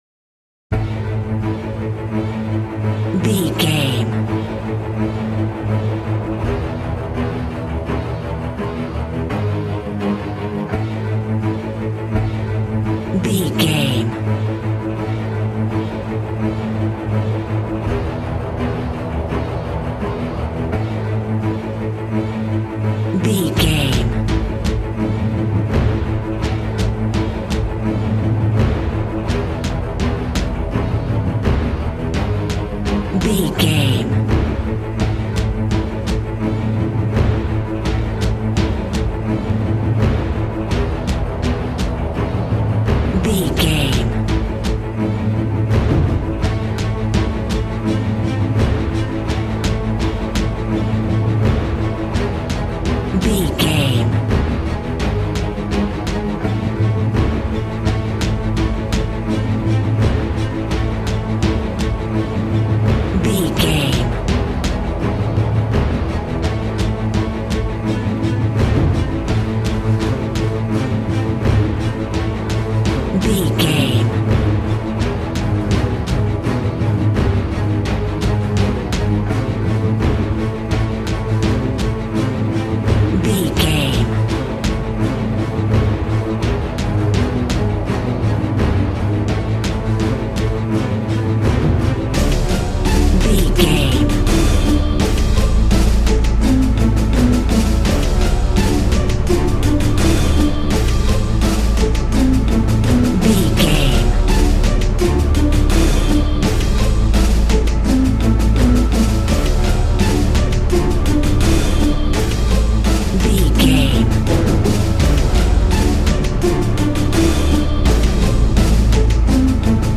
Ionian/Major
strings
percussion
synthesiser
brass
violin
cello
double bass